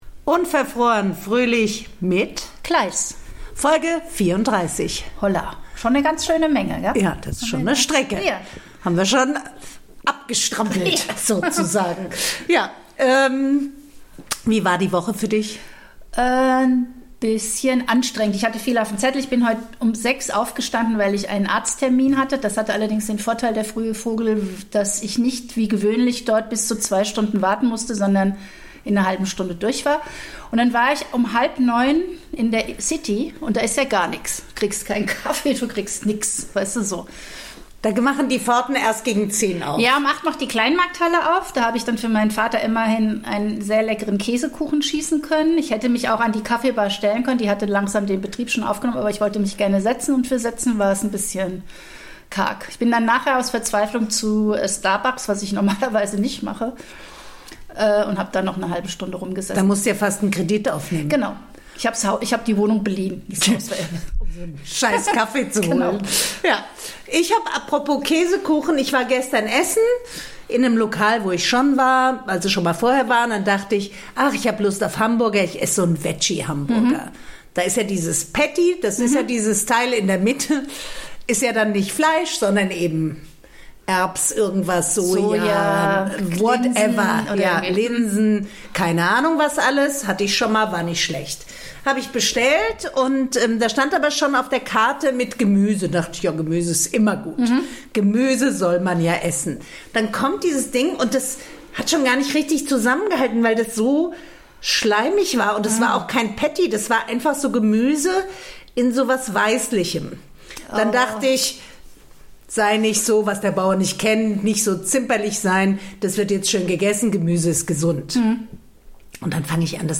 reden die beiden Podcasterinnen über Großmelternpflichten, Anspruchsdenken, unruhige Männer und Familienzusammenführung.